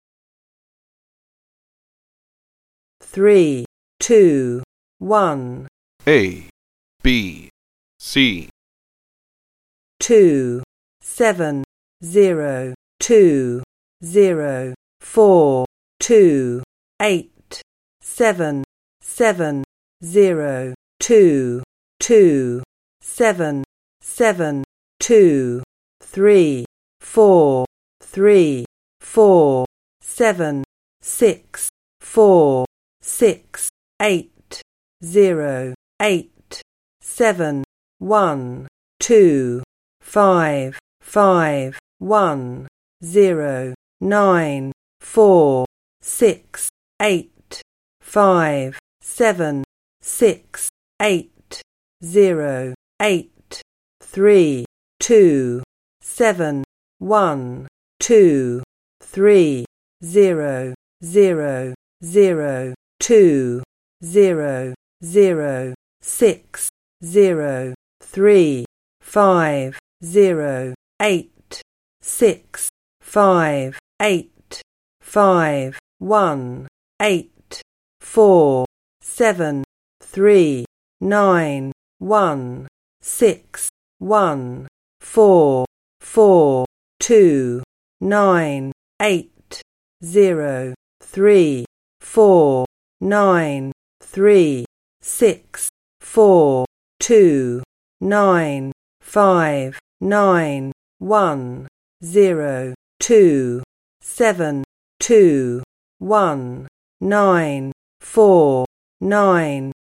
10 100 Spoken Numbers